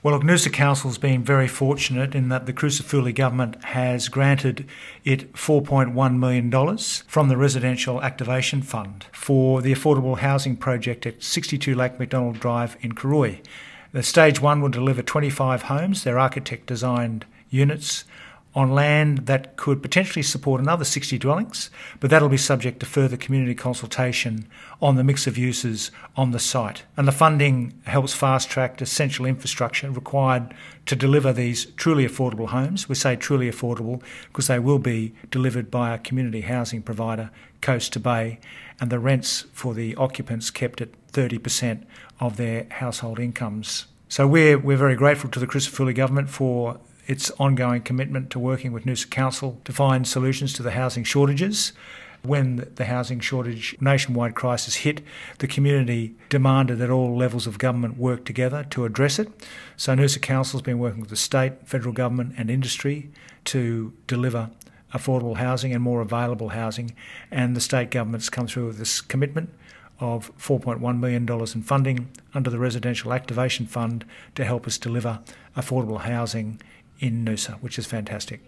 Mayor Frank Wilkie discusses the multi-million-dollar state government funding: